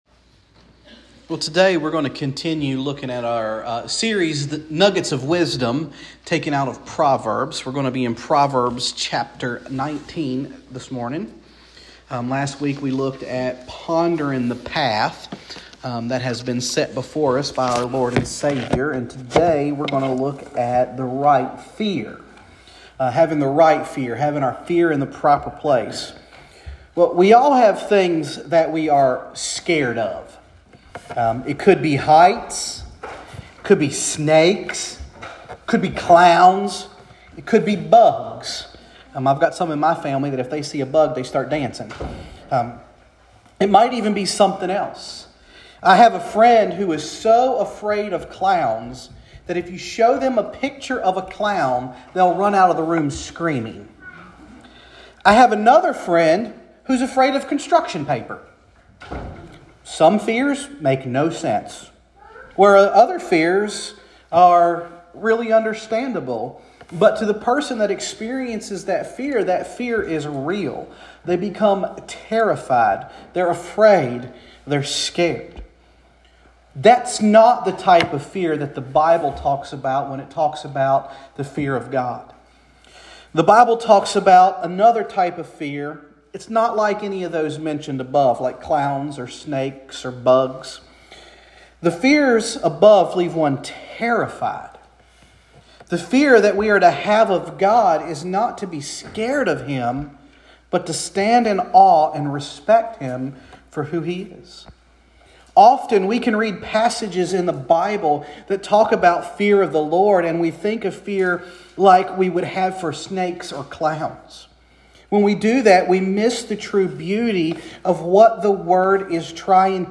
Sermons | Hopewell First Baptist Church